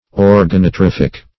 Search Result for " organotrophic" : The Collaborative International Dictionary of English v.0.48: Organotrophic \Or`ga*no*troph"ic\, a. [Organo- + Gr.
organotrophic.mp3